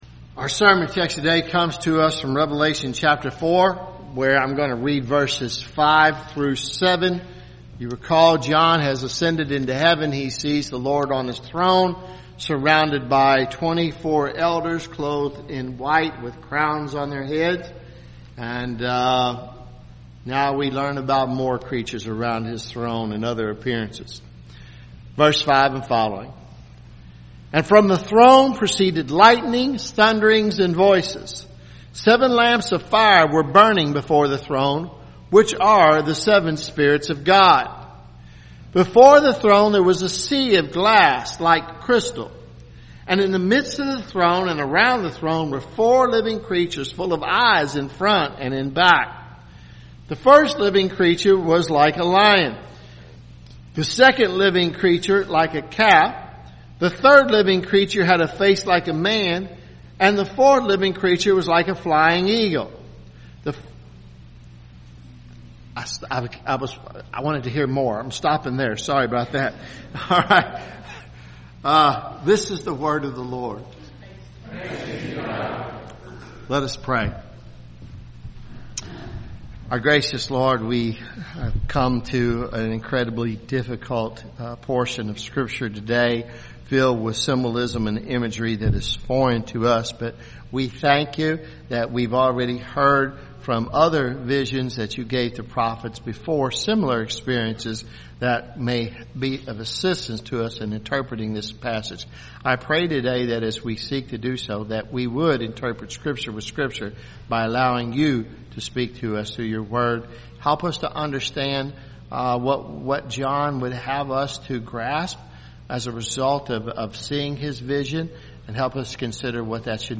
at Christ Covenant Presbyterian Church, Lexington, Ky.